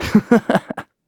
Kibera-Vox-Laugh.wav